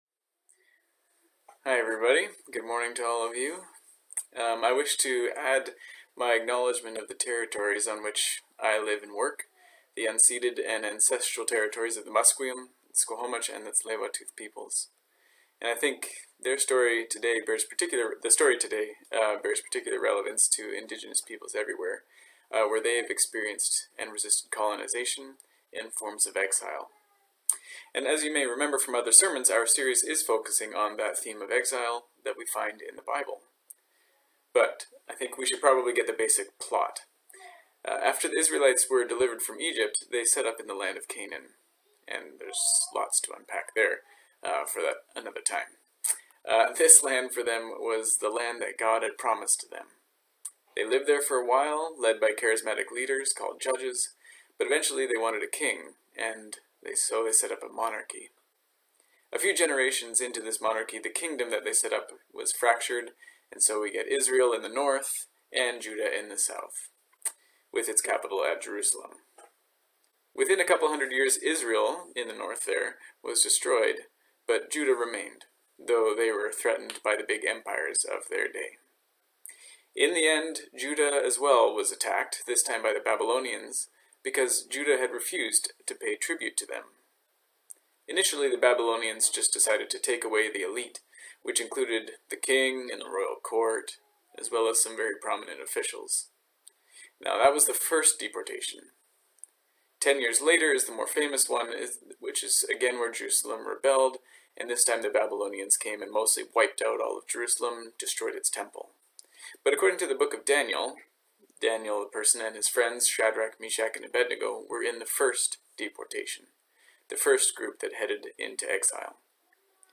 From this series From Death to Life Hope in the Midst of Exile Exile Ezekiel 1:1; 37:1-14 Guest Speaker May 24, 2020 Current Sermon Boss, You Can Burn Us!